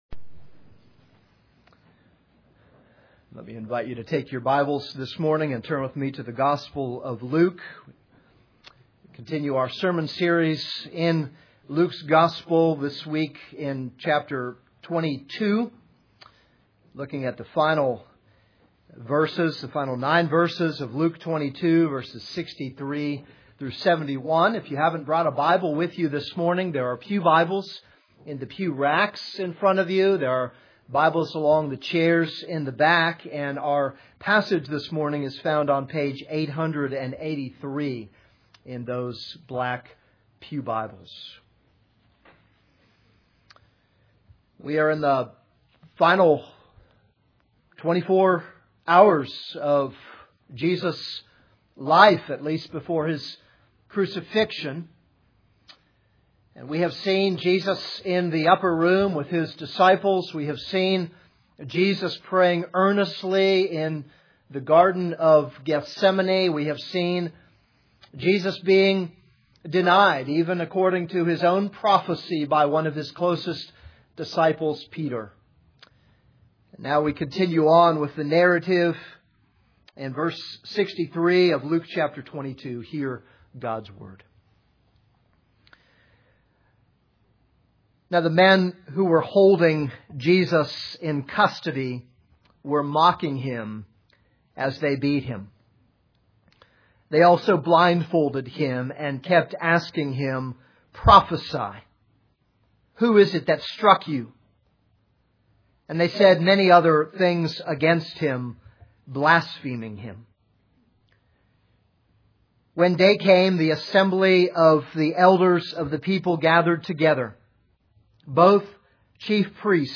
This is a sermon on Luke 22:63-71.